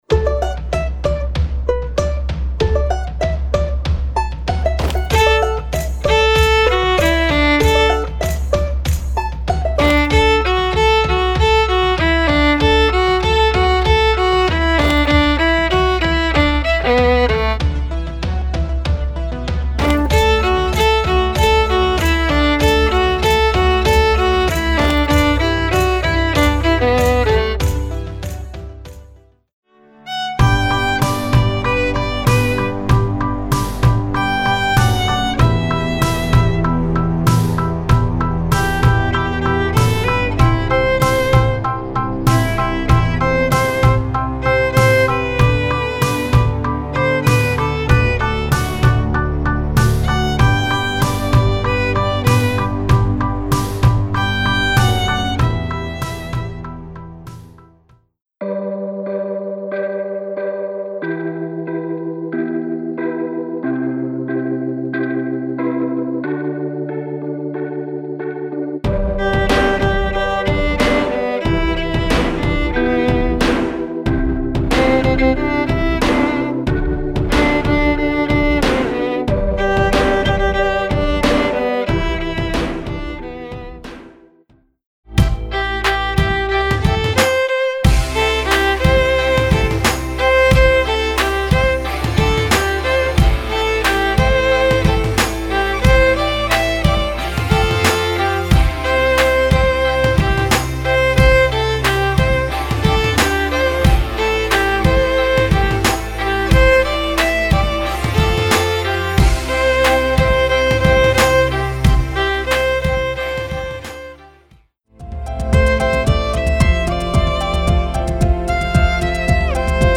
Voicing: Violin w/ Audio